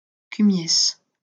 Cumiès (French pronunciation: [kymjɛs]